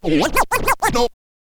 scratch_kit01_08.wav